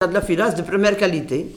Enquête Arexcpo en Vendée
Catégorie Locution